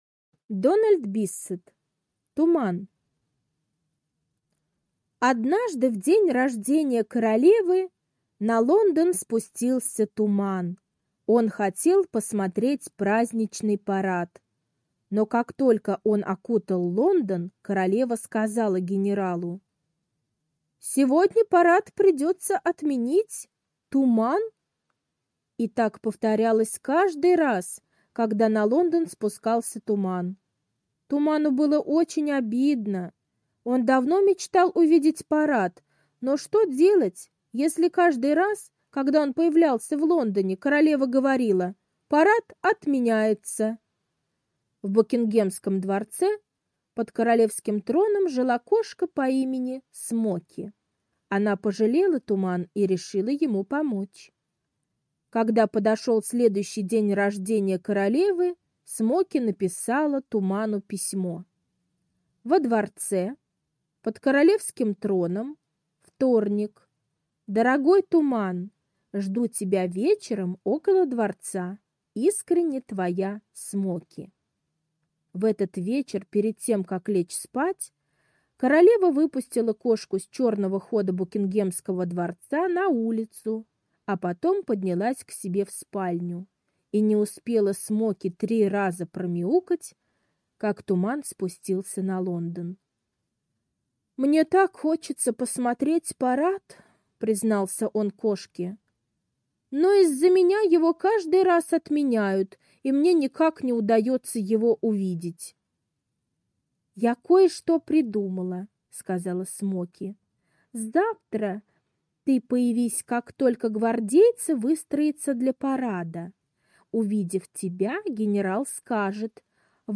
Туман - аудиосказка Биссета Д. Сказка про то, как кошка Смоки помогла туману посмотреть королевский парад.